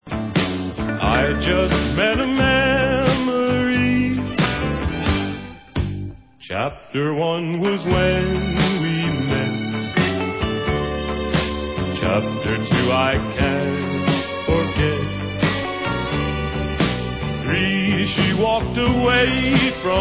sledovat novinky v oddělení Rock & Roll